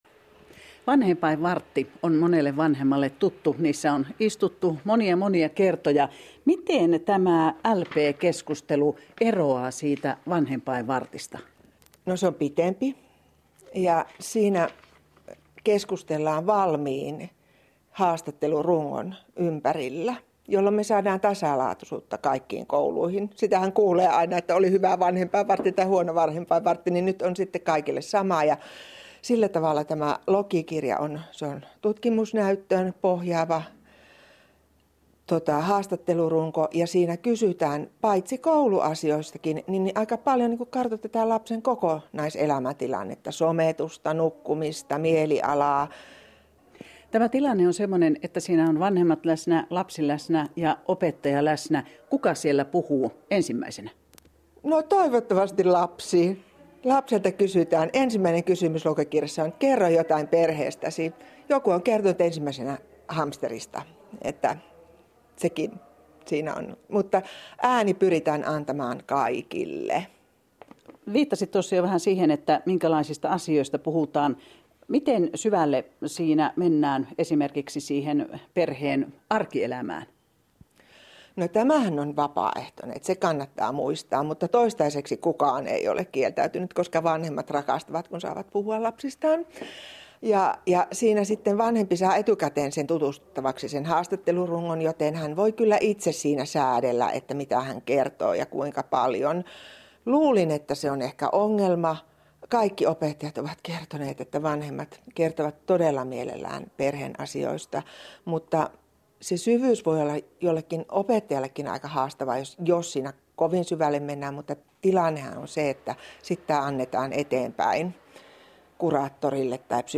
LP-koordinaattorin ja luokanohjaajan haastattelu (YLE Pohjois-Karjala, 4/2019)